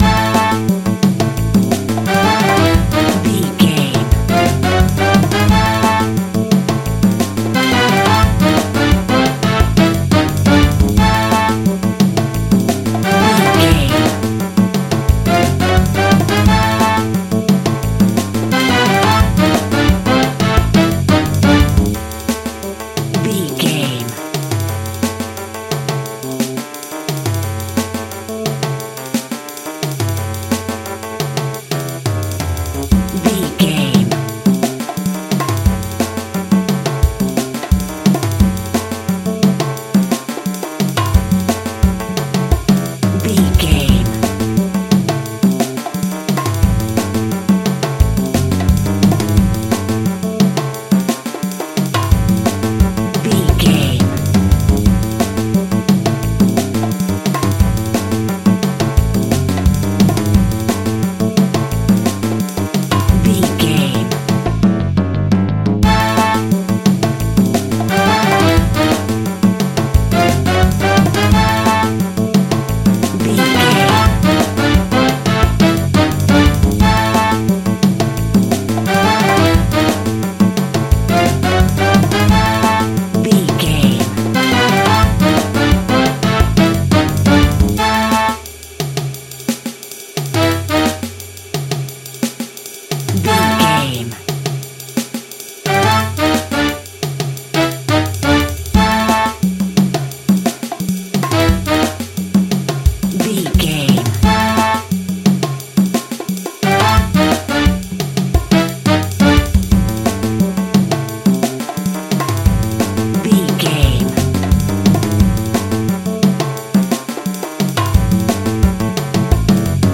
Aeolian/Minor
Fast
jazz funk
northern soul
groovy instrumental music
guitars
clavinet